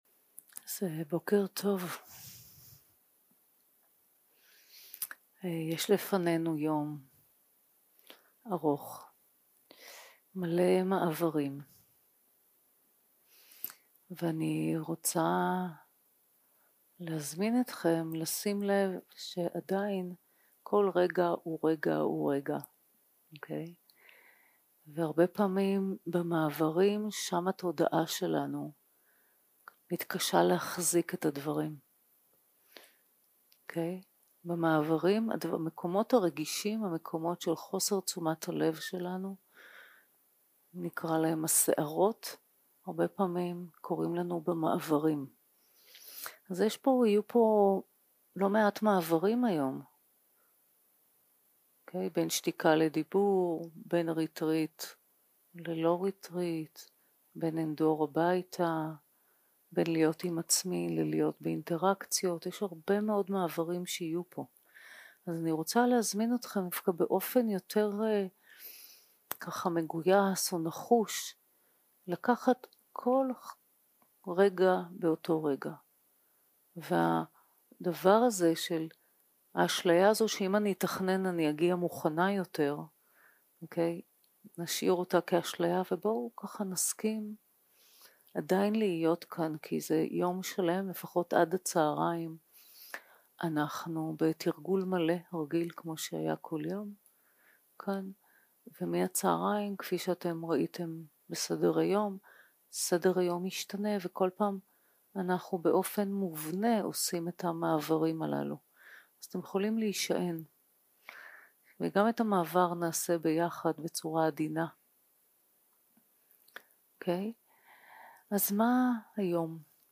יום 4 - הקלטה 7 - בוקר - הנחיות למדיטציה - גוף, נשימה, תשומת לב ושמיטה 0:00 0:00 סוג ההקלטה: סוג ההקלטה: שיחת הנחיות למדיטציה שפת ההקלטה: שפת ההקלטה: עברית